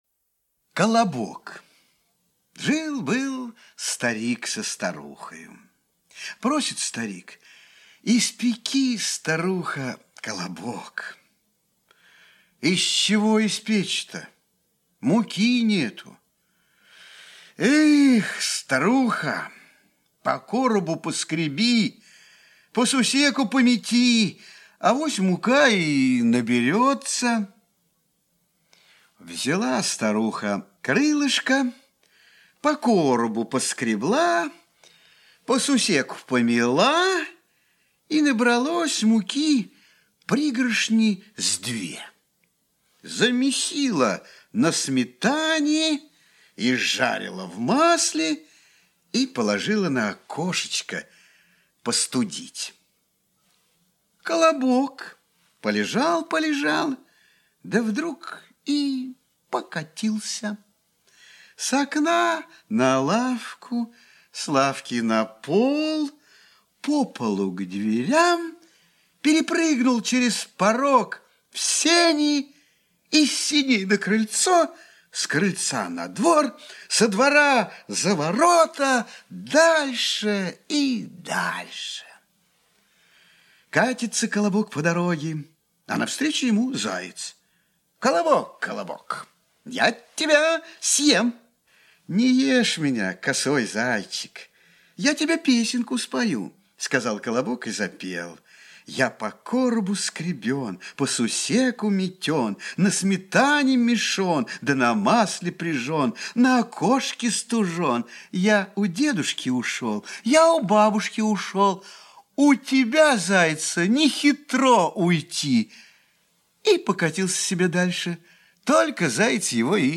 Аудиокнига Мне 1 год | Библиотека аудиокниг
Aудиокнига Мне 1 год Автор Коллектив авторов Читает аудиокнигу Актерский коллектив.